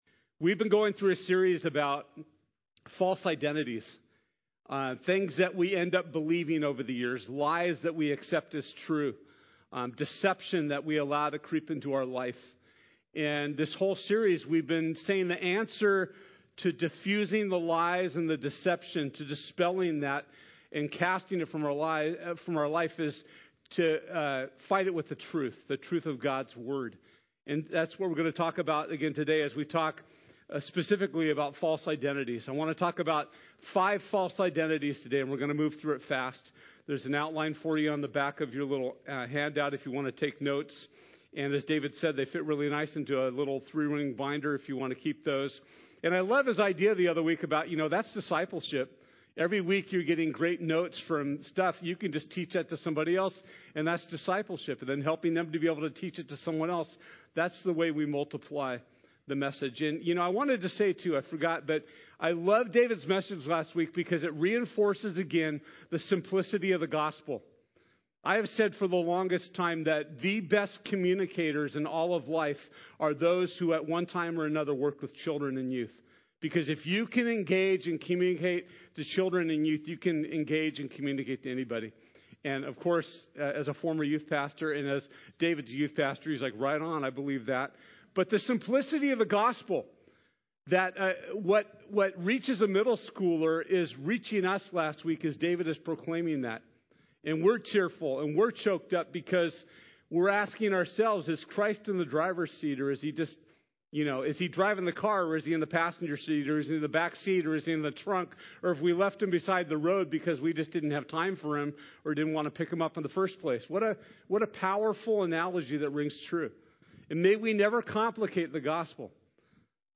Free Indeed Service Type: Sunday This week in our teaching time we’ll be talking about the False Identities that we mistakenly buy into.